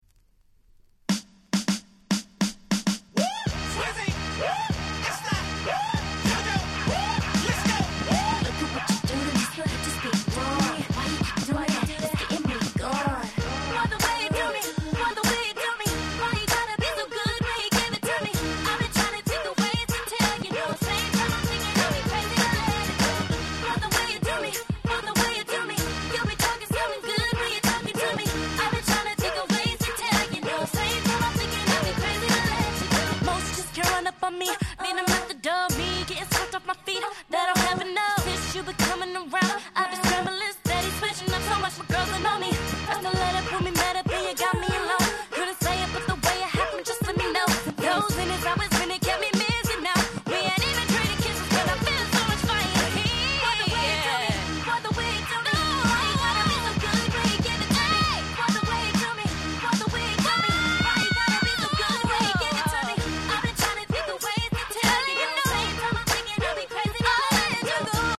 06' Smash Hit R&B !!